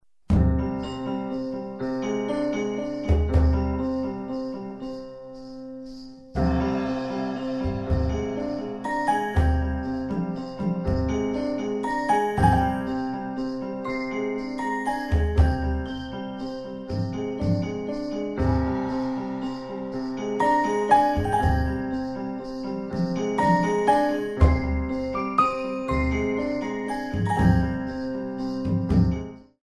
6/8  mm=120